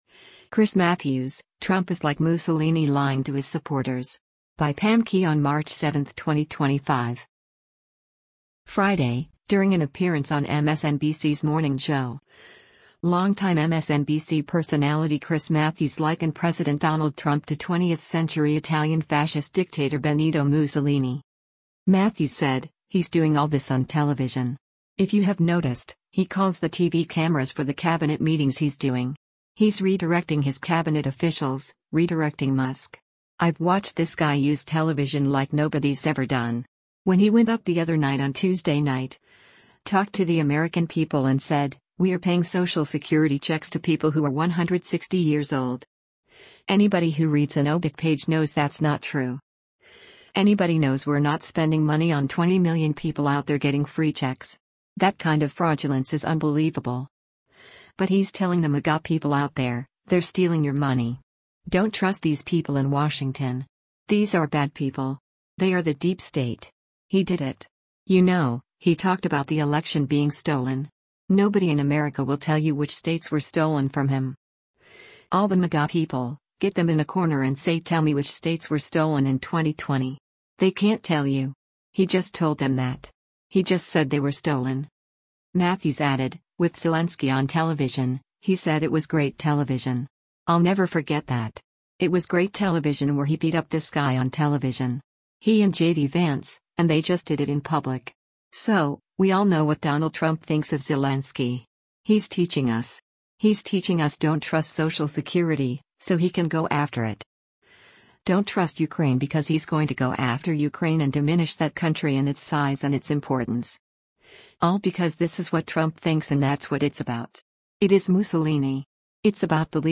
Friday, during an appearance on MSNBC’s “Morning Joe,” long-time MSNBC personality Chris Matthews likened President Donald Trump to 20th-century Italian fascist dictator Benito Mussolini.